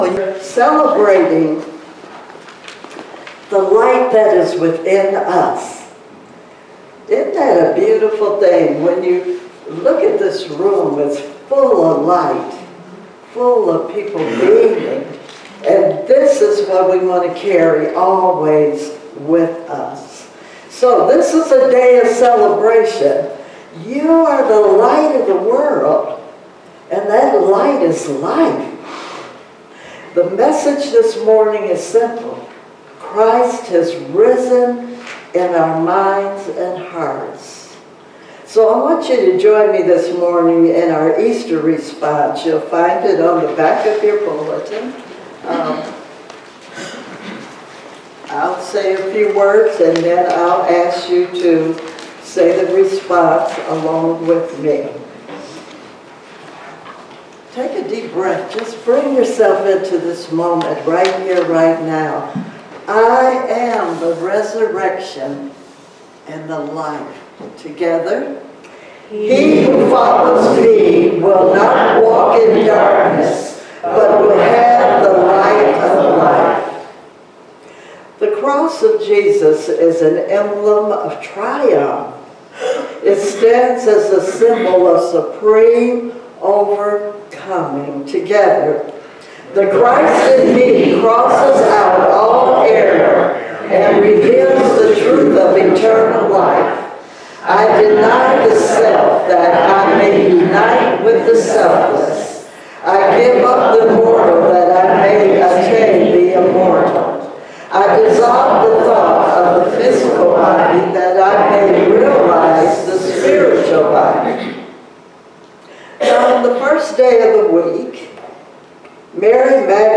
Series: Sermons 2024